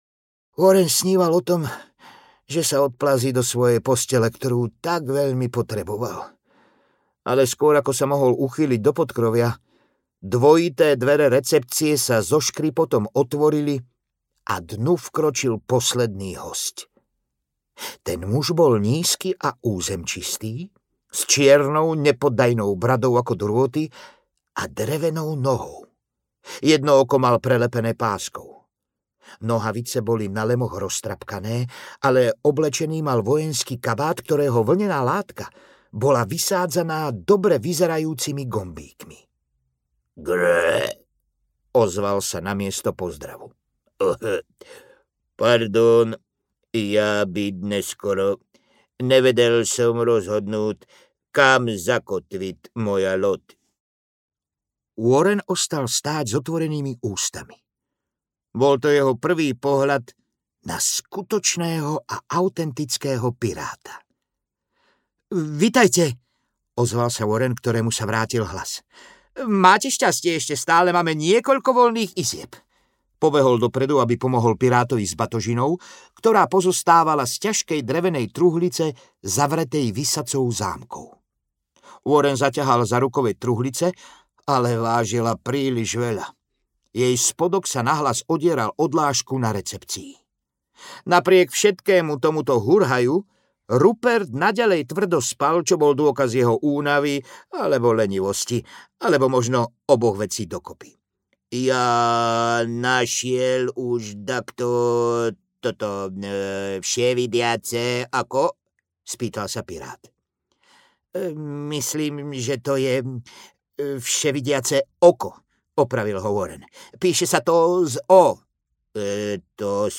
Warren trinásty a Vševidiace oko audiokniha
Ukázka z knihy